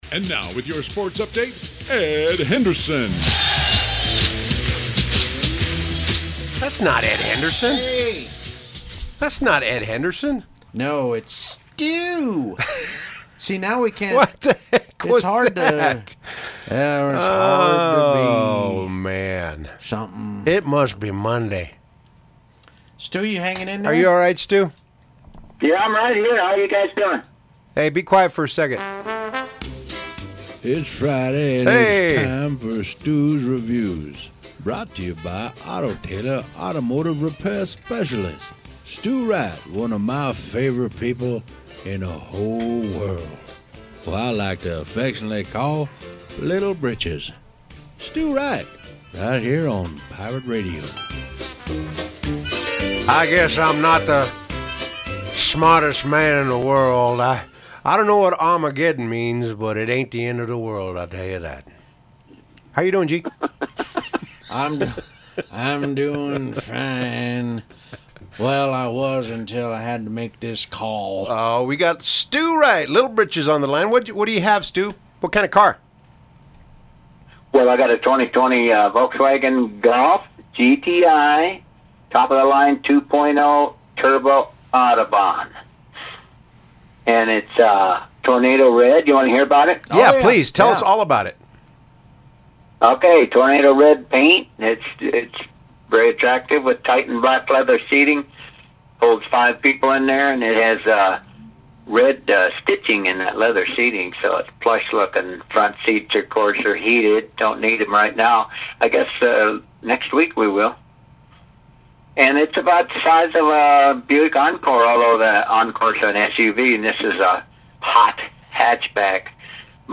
Radio Review: